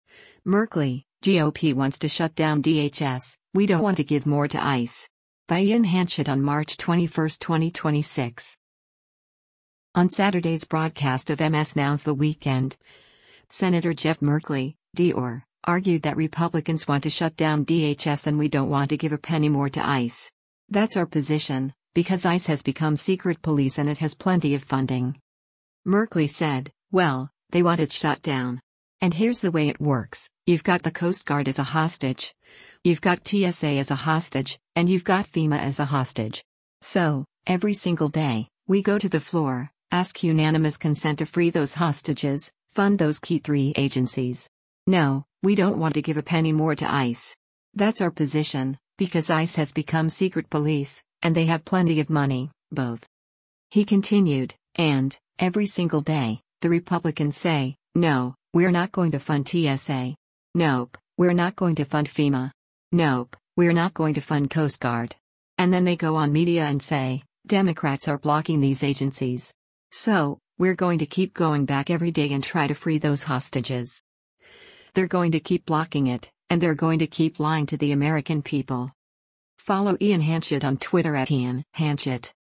On Saturday’s broadcast of MS NOW’s “The Weekend,” Sen. Jeff Merkley (D-OR) argued that Republicans want to shut down DHS and “we don’t want to give a penny more to ICE. That’s our position, because ICE has become secret police” and it has plenty of funding.